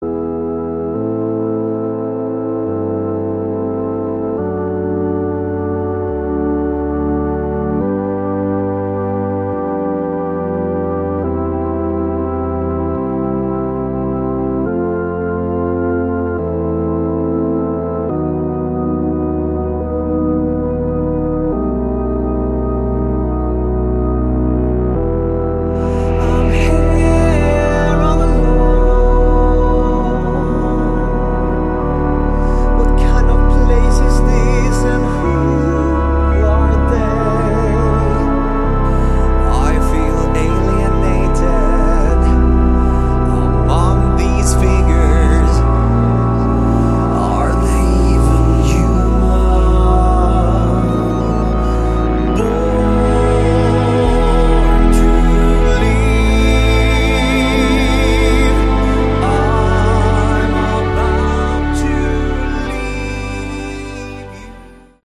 Category: Prog Rock